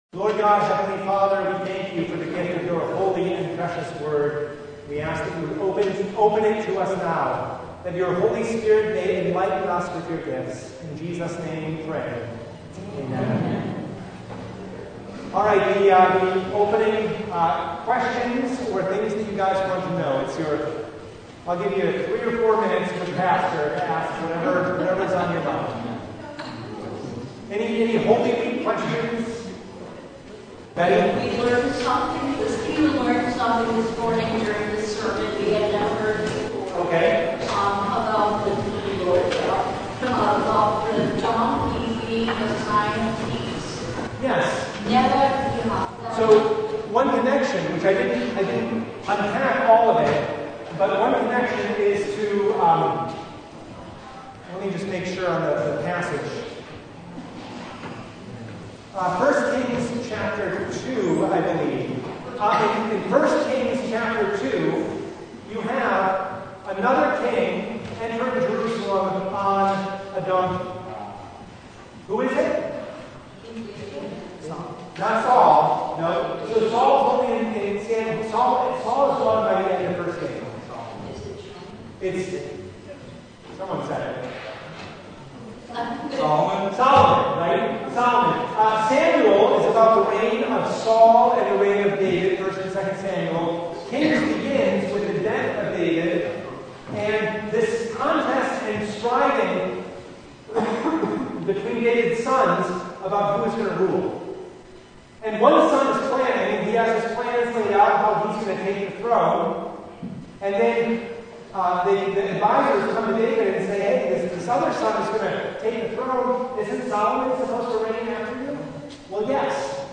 A question and answer discussion regarding topics related to holy week.
Service Type: Bible Study